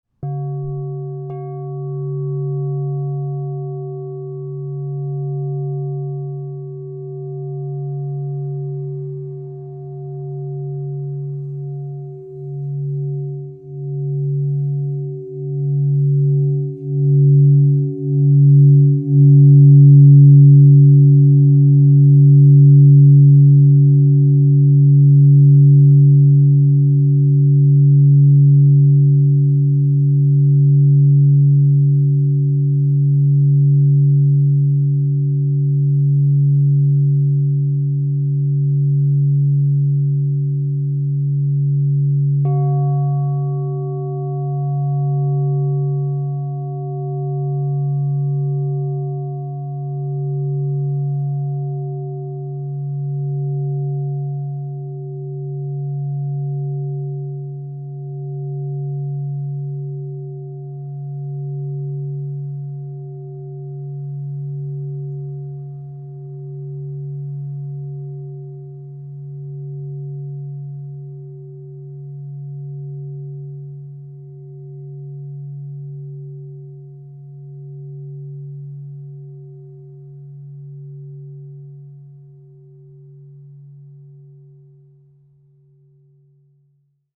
Charcoal 12" C# +40 - Divine Sound